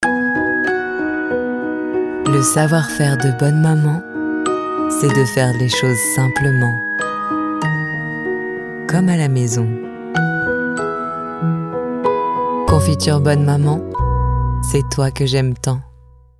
Naturelle, Distinctive, Polyvalente, Fiable, Chaude